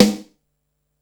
• 2000s Subby Snare Drum Sample G# Key 61.wav
Royality free snare drum sample tuned to the G# note.